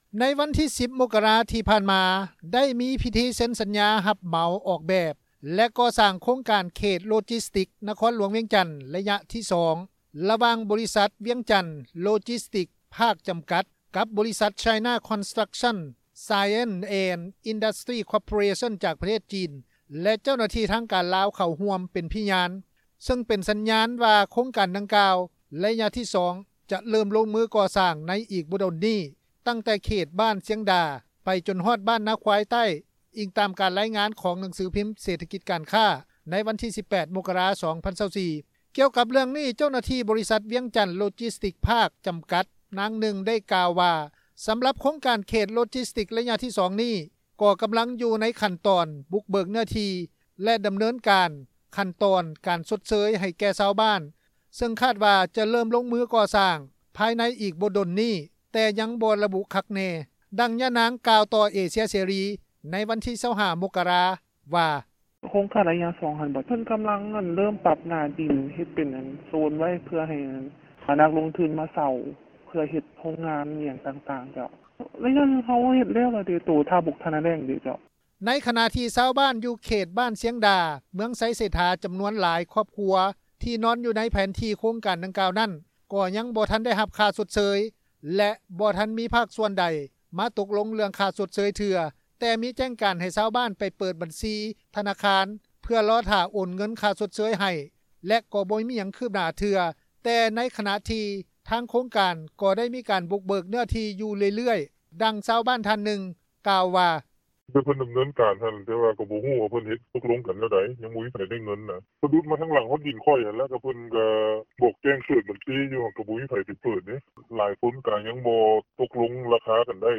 ດັ່ງຊາວບ້ານ ທ່ານນຶ່ງ ກ່າວວ່າ:
ດັ່ງຊາວບ້ານໃກ້ຄຽງ ນາງນຶ່ງກ່າວວ່າ: